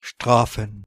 The word is an adaptation of German strafen (pronounced [ˈʃtʁaːfn̩]